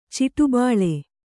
♪ ciṭu bāḷe